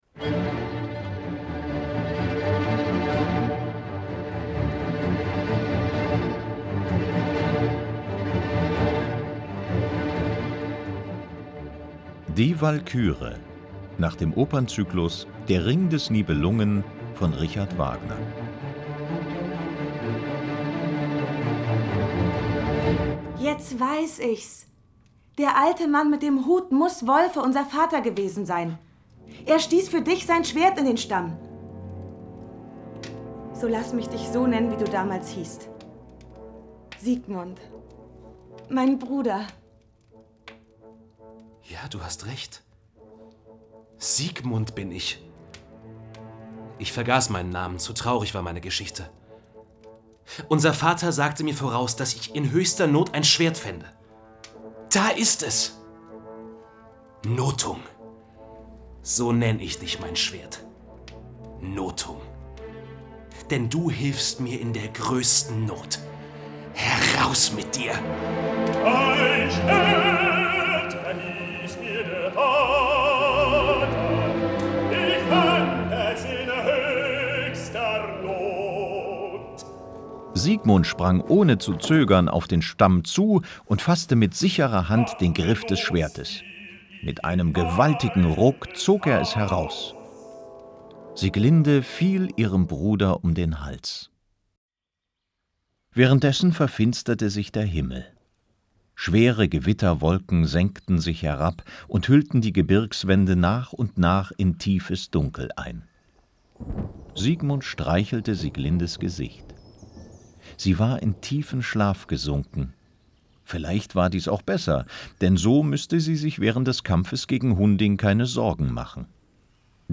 Die Walküre, Der Ring des Nibelungen für kleine Hörer, Die ZEIT-Edition Hörspiel mit Opernmusik